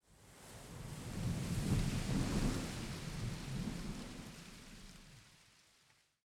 housewind04.ogg